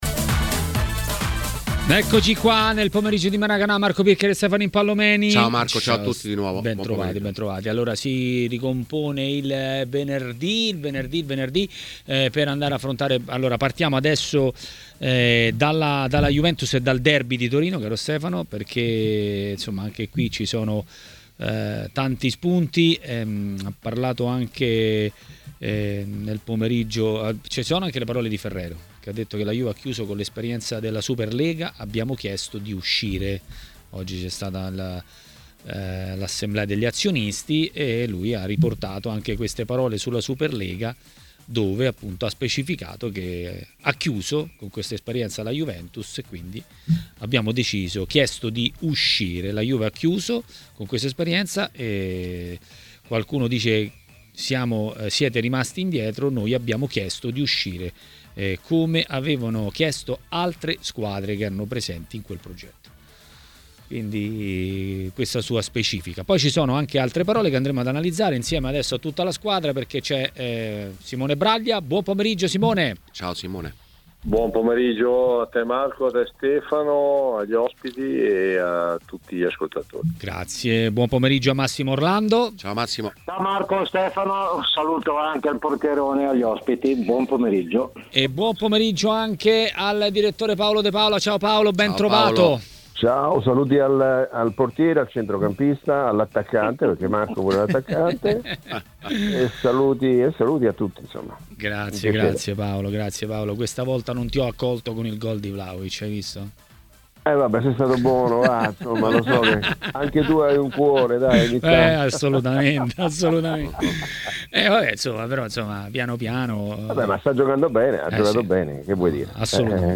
A parlare dei temi del giorno a TMW Radio, durante Maracanà, è stato l'ex calciatore Massimo Orlando.